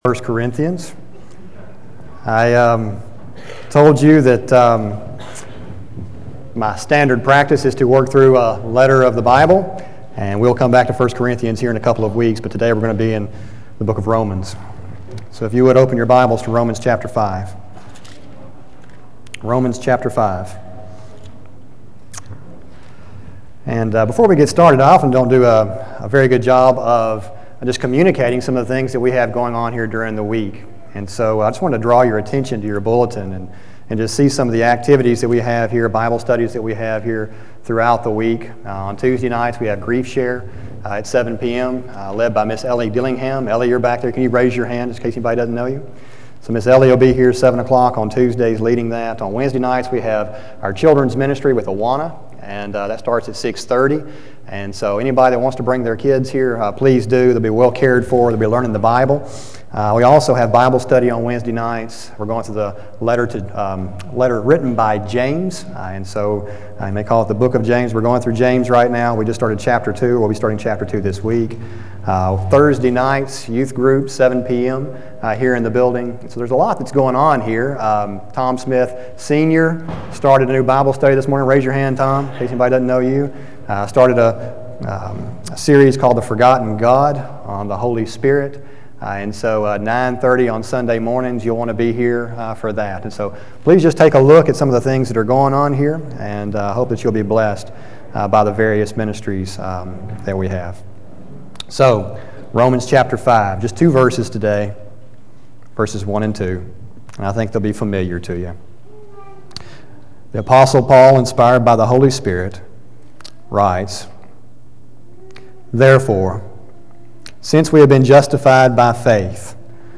sermon032314b.mp3